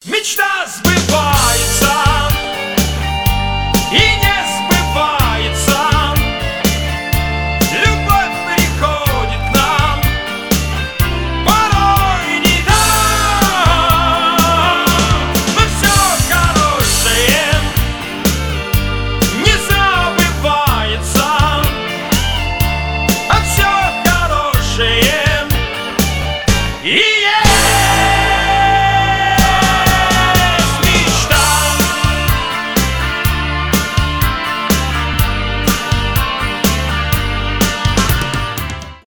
ретро , поп